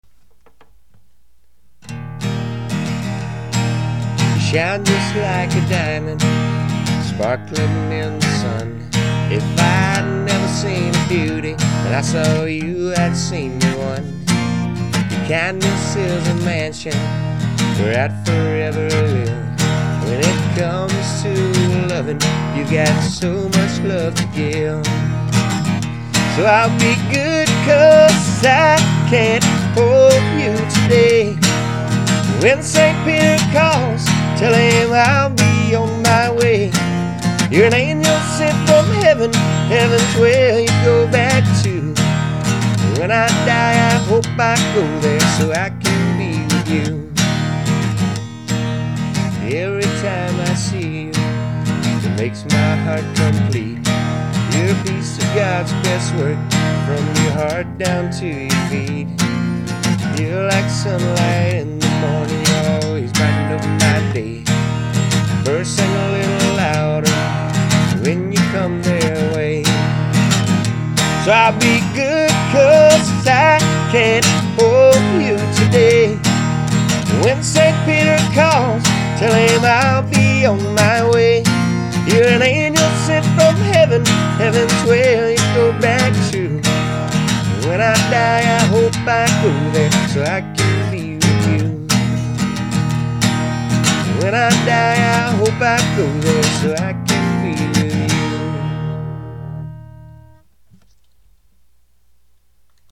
Country-rock